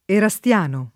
Erasto [er#Sto] pers. m. stor.